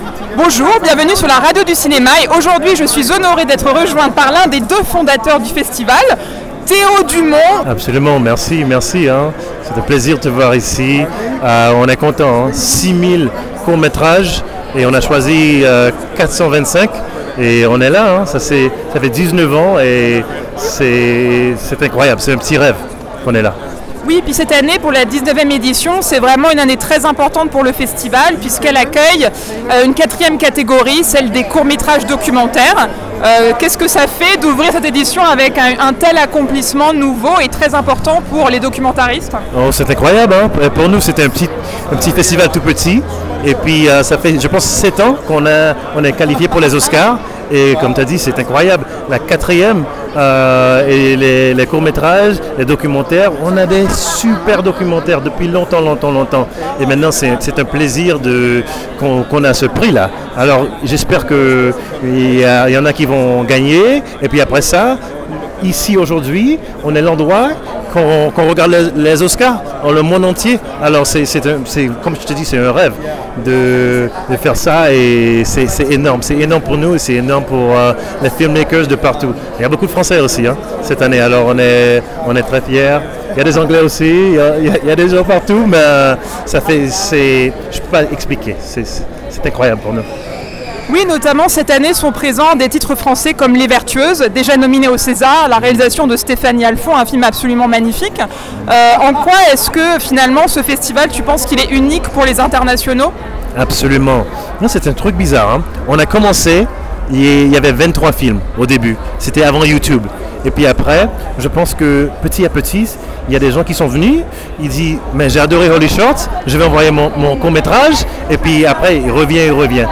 Enfin, l'entretien a abordé le rôle du festival dans la promotion des réalisatrices et des femmes derrière et devant la caméra.